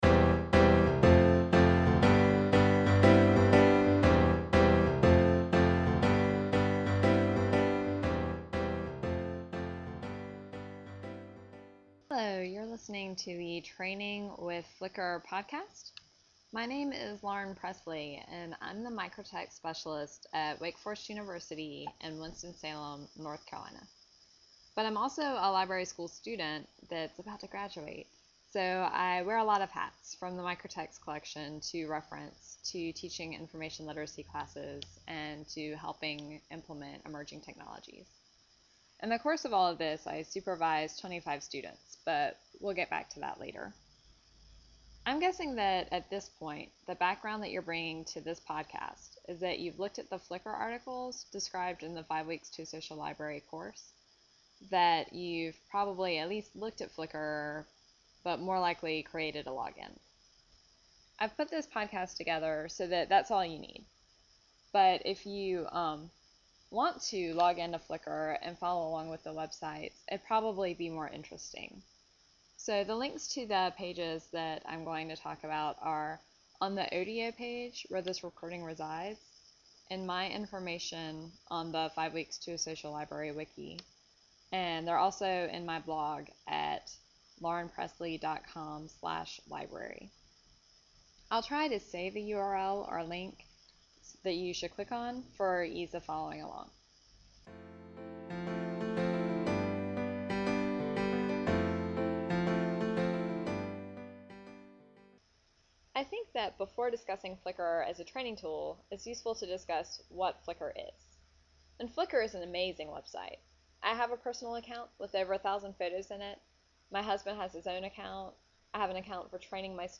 Item type: Presentation Keywords: flickr training wiki Subjects: L. Information technology and library technology > LZ.